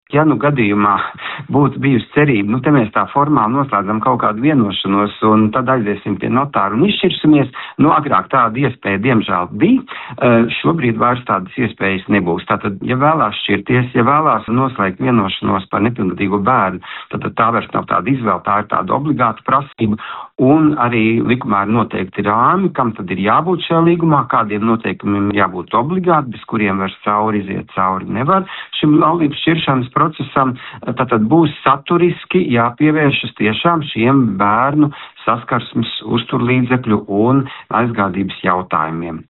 intervijā Skonto mediju grupai (SMG)